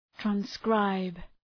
Προφορά
{træn’skraıb}